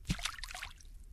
splash1.wav